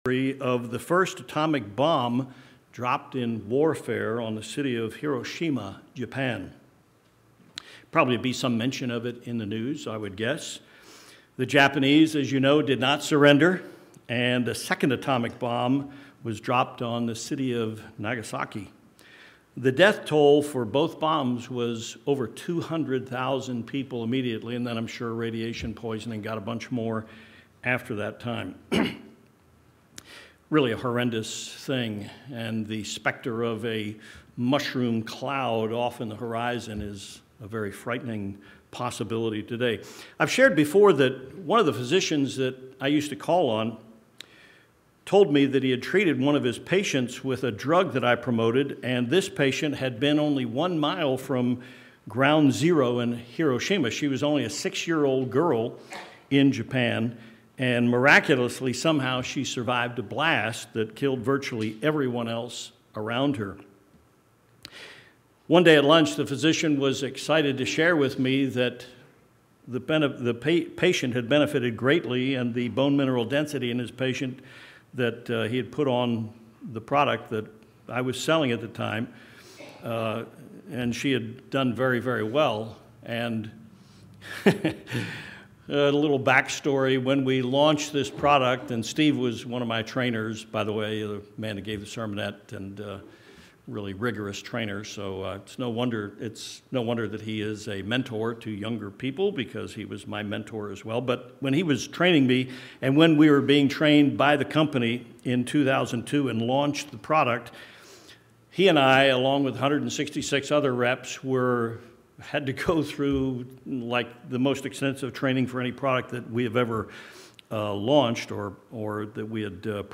The world is on the brink of World War 3 with the atomic clock at 90 seconds to midnight. This sermon shows that it is the leaders of the nations that have taken us to the brink of destruction.